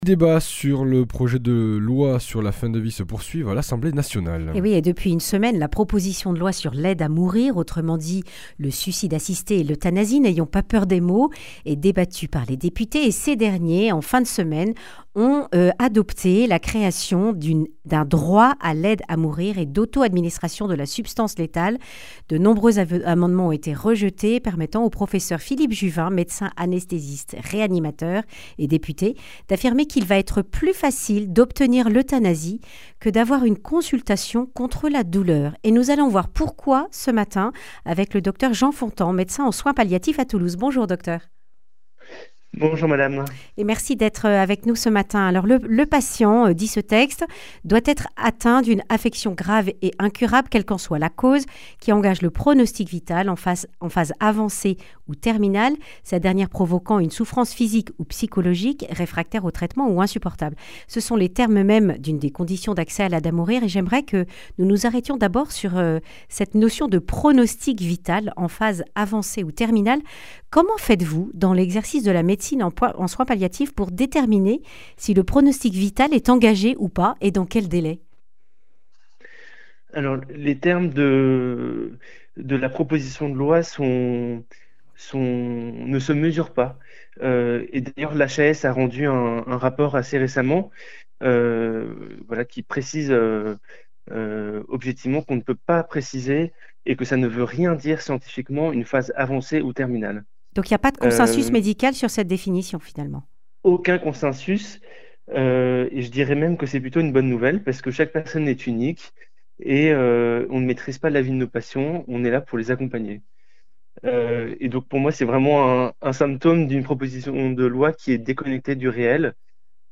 Accueil \ Emissions \ Information \ Régionale \ Le grand entretien \ Il n’y a pas de consensus médical sur la définition du pronostic vital (…)